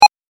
文字送りb単.mp3